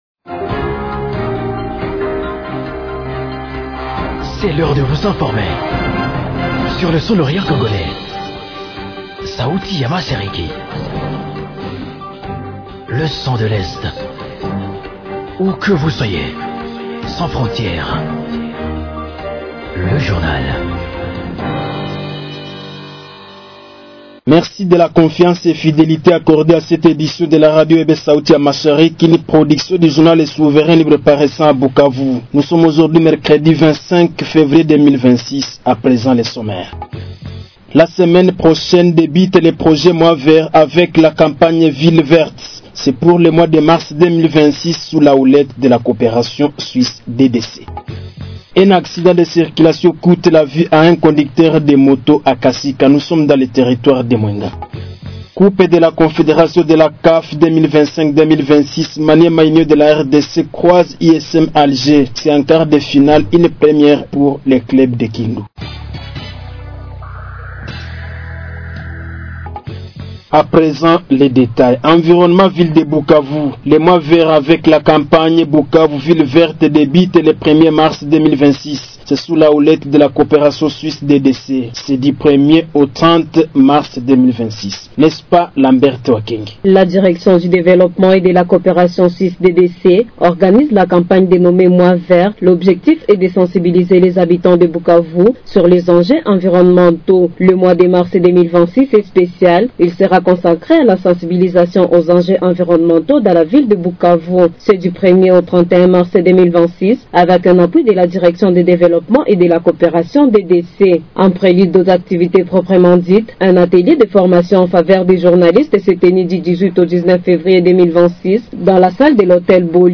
Journal du 25.02.2026